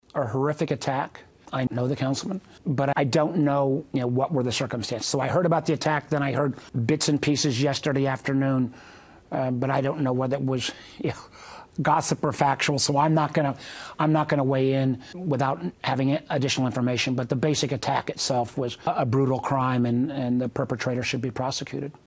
During media availabilities on Thursday, both U.S. Senators from Virginia reacted to the attack.